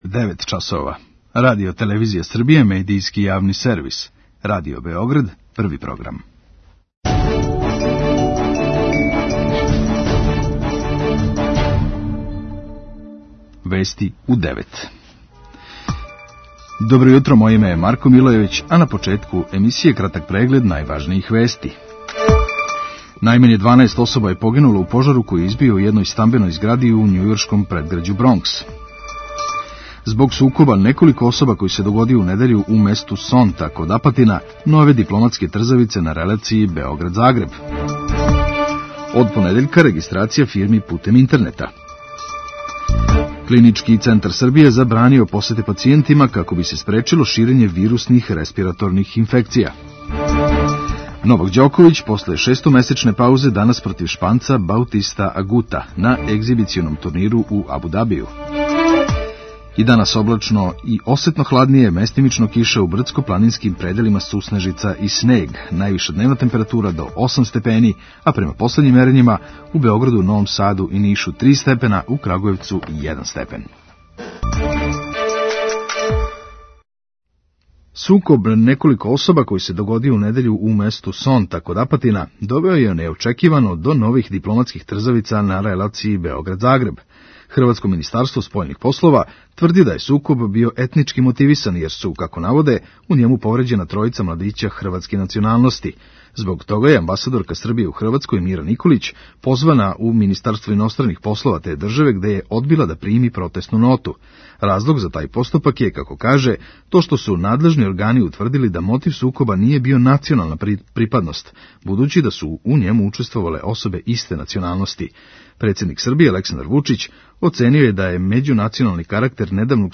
преузми : 3.99 MB Вести у 9 Autor: разни аутори Преглед најважнијиx информација из земље из света.